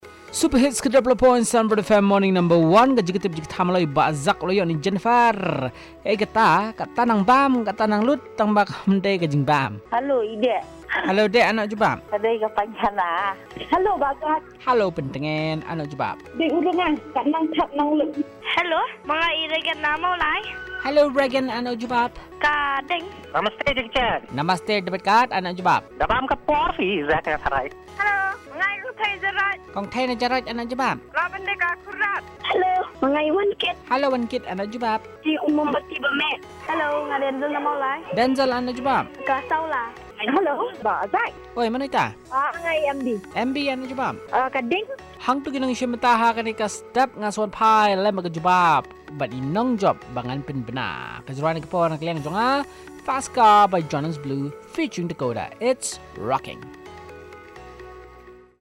Callers with their answers